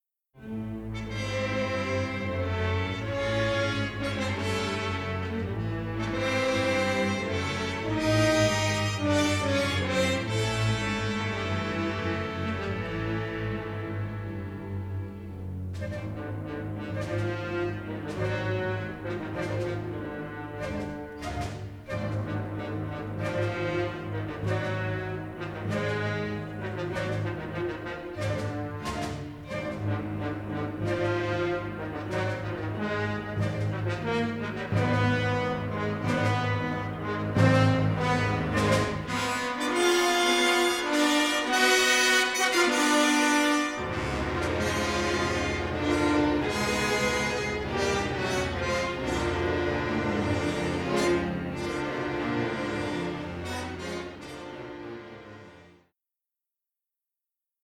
rich symphonic score
three-channel stereo scoring session masters